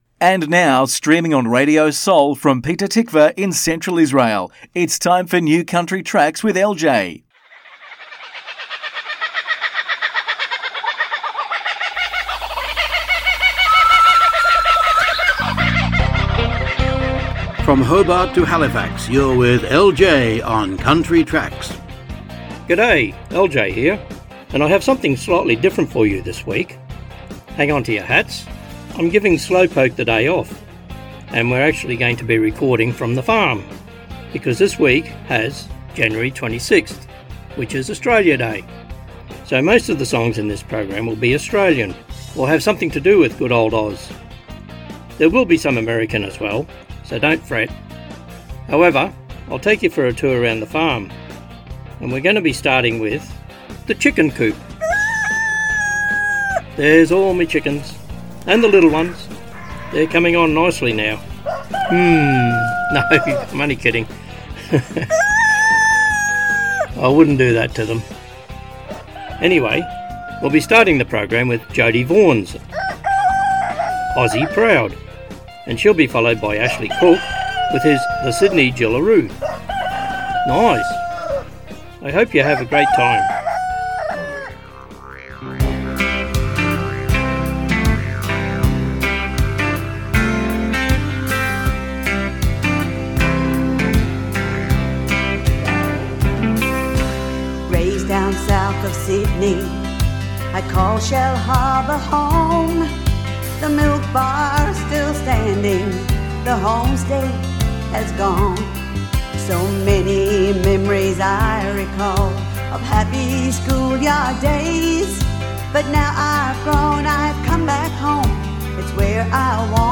מוזיקת קאנטרי ואינדי עולמית - התכנית המלאה 24.1.25